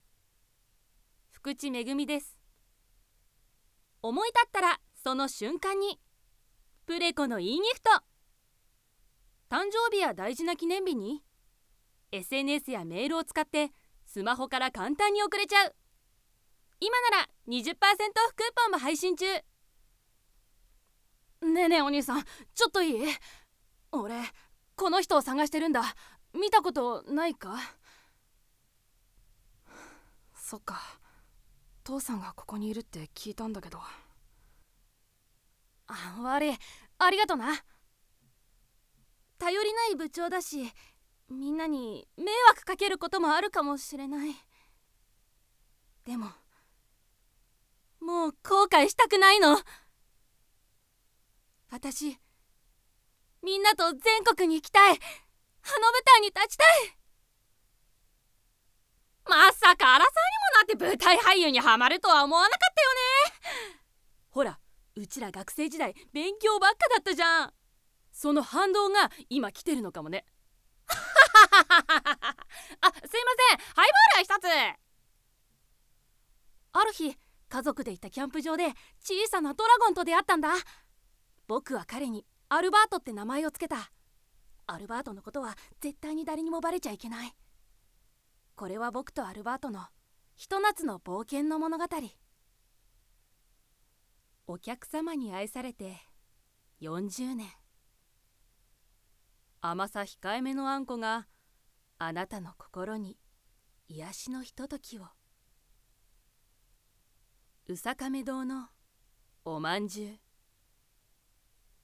方　言　：　東京都
ボイスサンプル